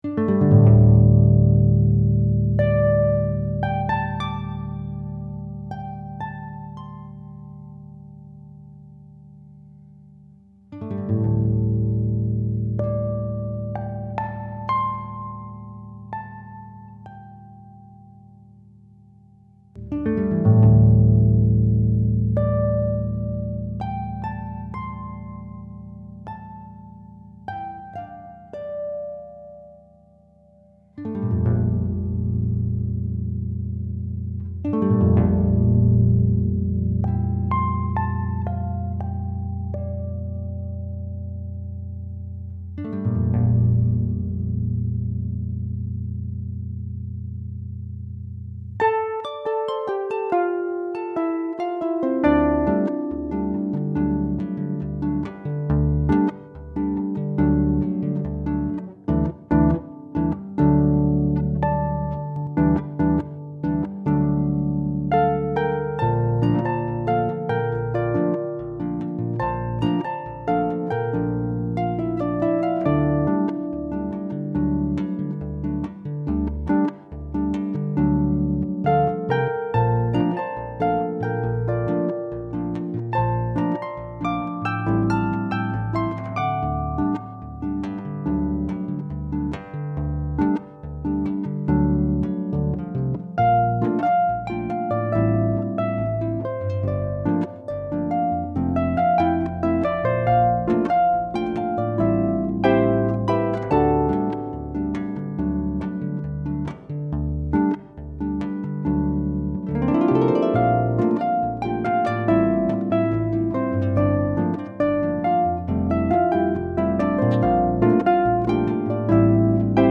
PEDAL HARP
An original, upbeat, Latin Jazz feel that is fun to play!
The piece depicts kind of a weightless feeling.
Performed at the 2021 Harp In Worship Conference.